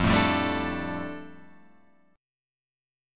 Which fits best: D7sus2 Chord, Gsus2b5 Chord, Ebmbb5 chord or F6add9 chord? F6add9 chord